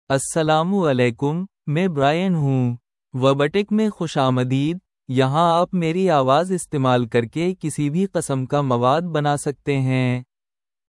Brian is a male AI voice for Urdu (India).
Voice sample
Listen to Brian's male Urdu voice.
Male
Brian delivers clear pronunciation with authentic India Urdu intonation, making your content sound professionally produced.